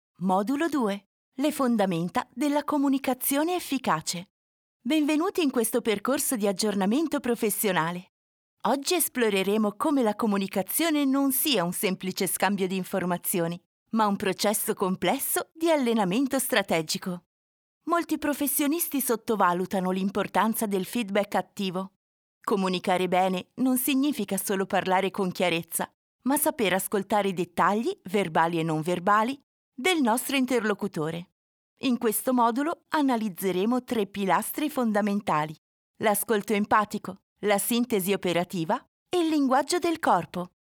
Young and lively voice, that can go from a soft sound, to a super enthusiastic tone.
Sprechprobe: eLearning (Muttersprache):
Neumann TLM 103 Focusrite Scarlett 2i2 4th gen ProTools
E-learning - Communication.mp3